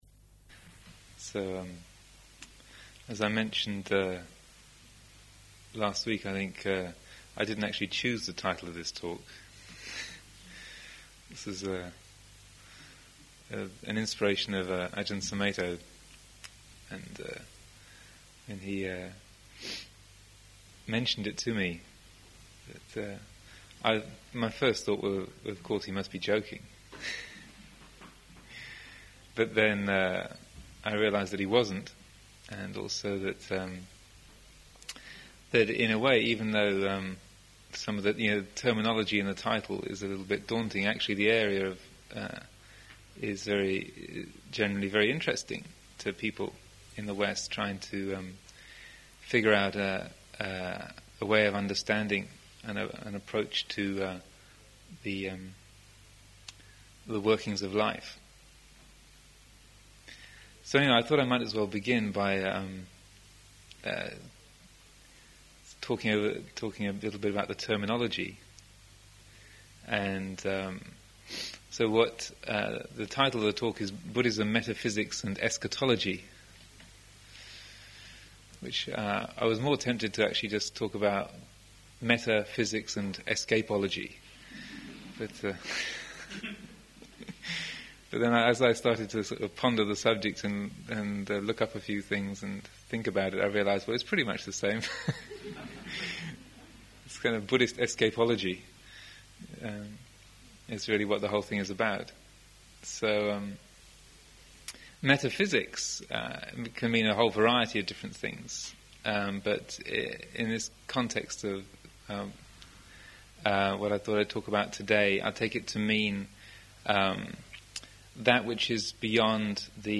Sunday Talks